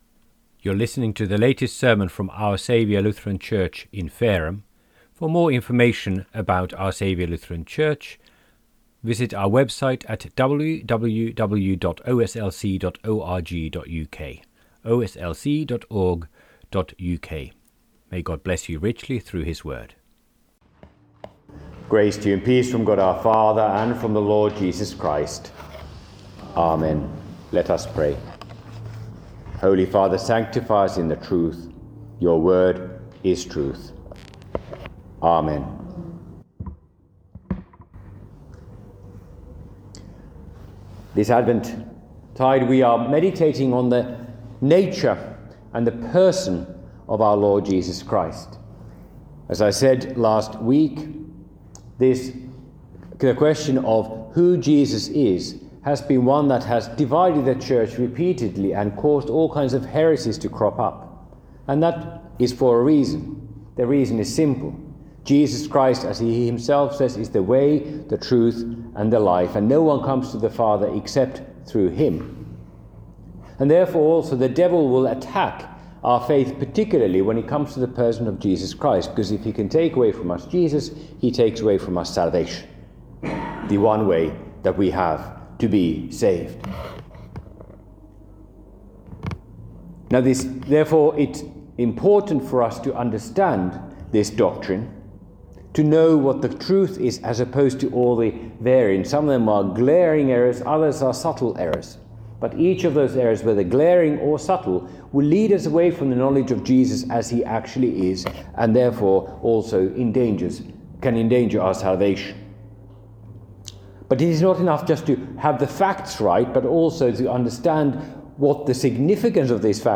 by admin | Dec 11, 2025 | Advent, Advent 2, Midweek Vespers, Sermons